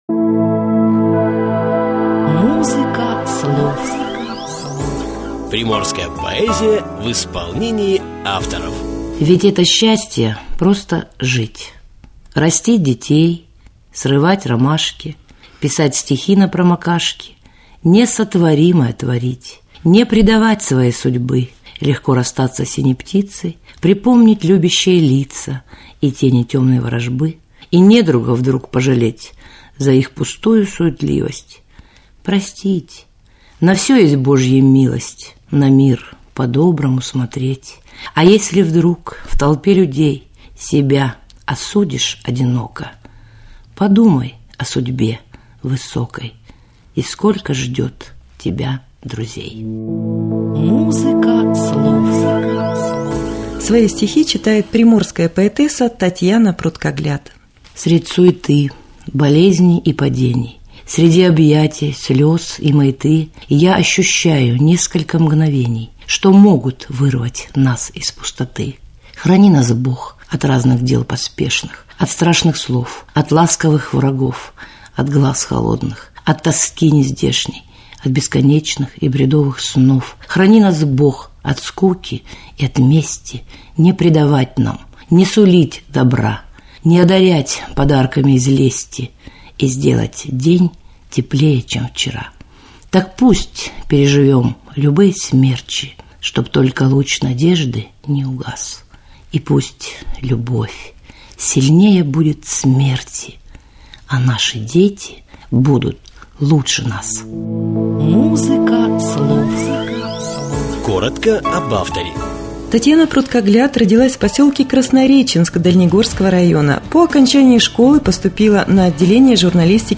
МУЗЫКА СЛОВ Чтение стихотворений в исполнении авторов. Записи для цикла радиопередач "Музыка Слов".